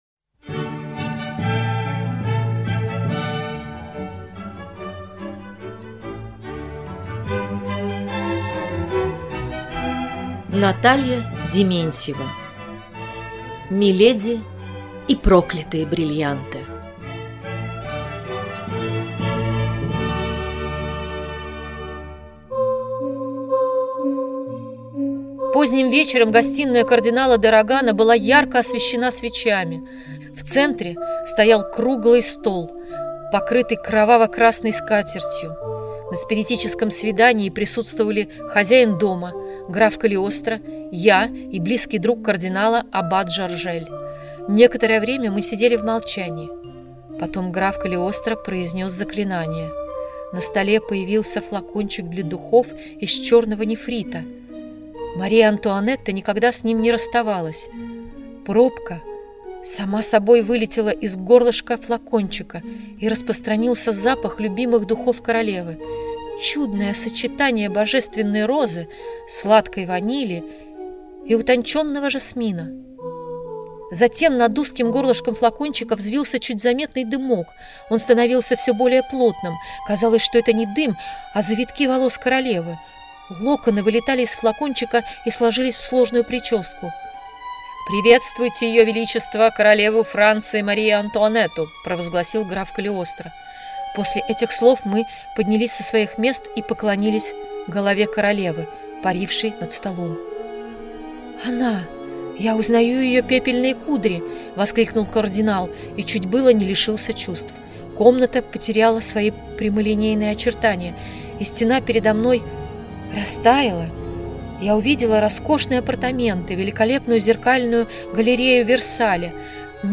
Литературные чтения (20:45)